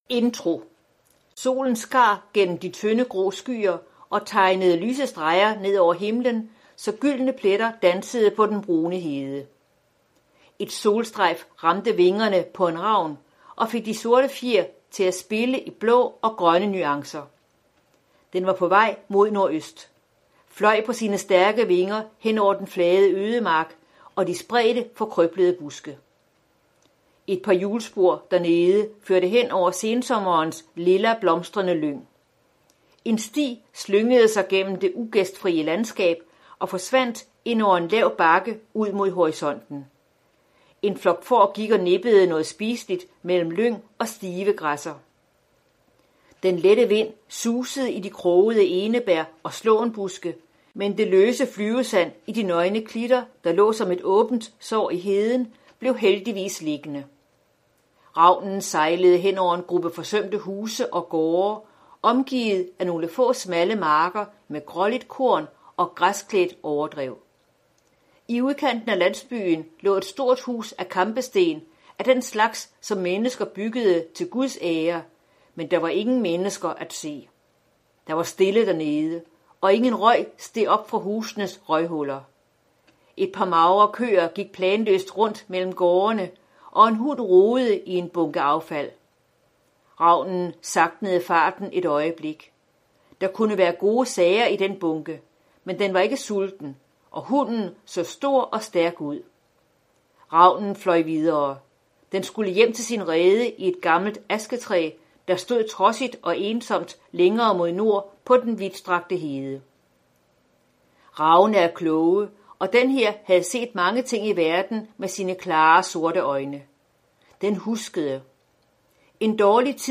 Hør et uddrag af I skyggen af pesten I skyggen af pesten Format MP3 Forfatter Anette Broberg Knudsen Bog Lydbog 49,95 kr.